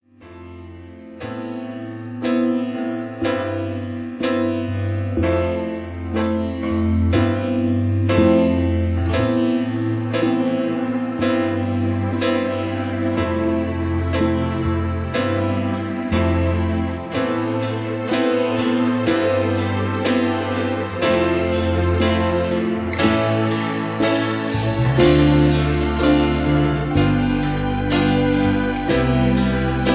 (Version-2, Key-A) Karaoke MP3 Backing Tracks
Just Plain & Simply "GREAT MUSIC" (No Lyrics).